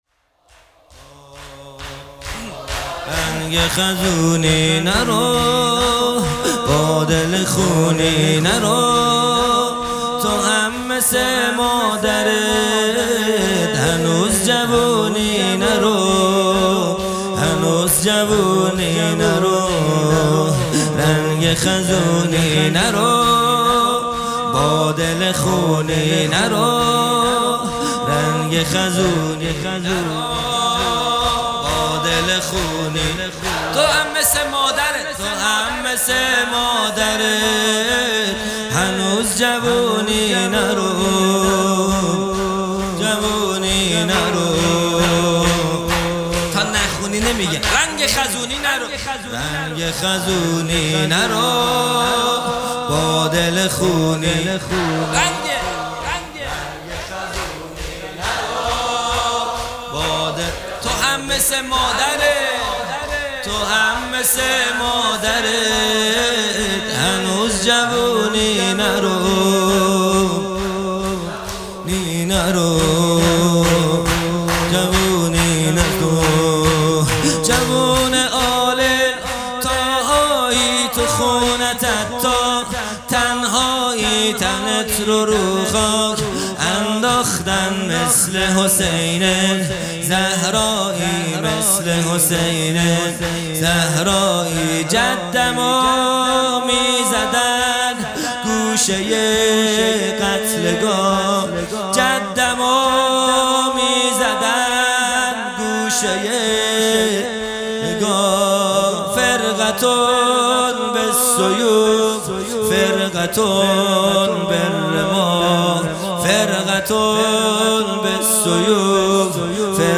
خیمه گاه - هیئت بچه های فاطمه (س) - شور | رنگ خزونی نرو | 8 تیر 1401